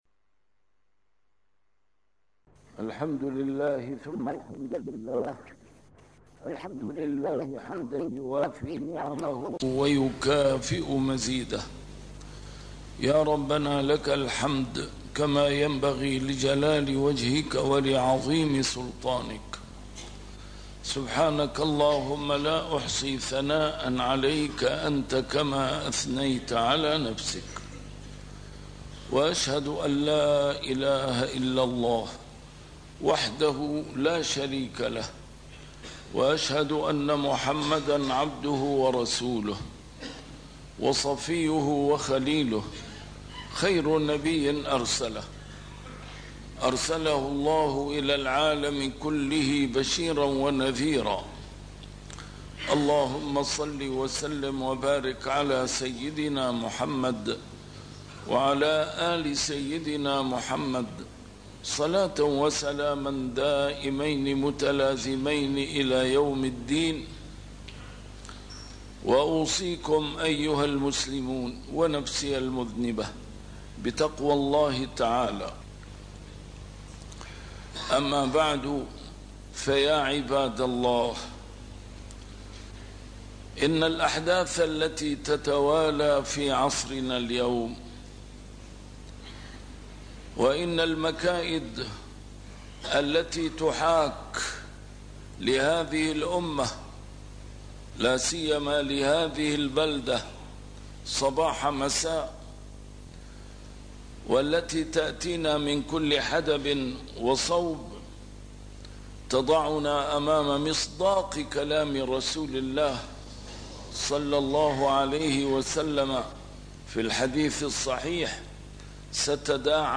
A MARTYR SCHOLAR: IMAM MUHAMMAD SAEED RAMADAN AL-BOUTI - الخطب - السبيل لثبات في المكائد والمحن